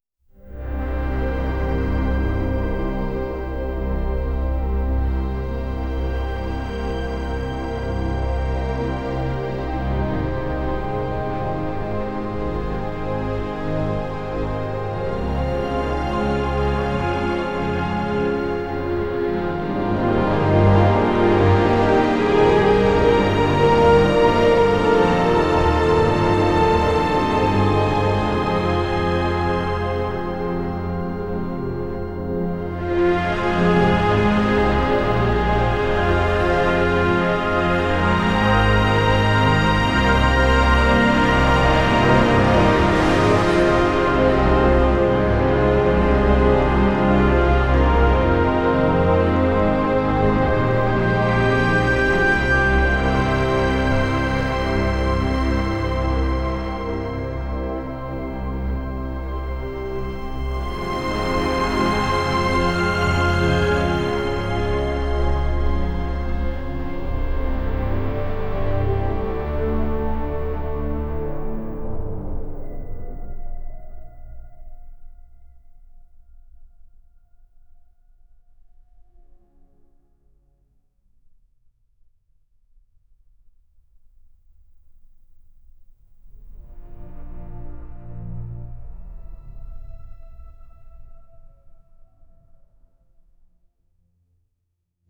ChoirChordsBassMaster_1.wav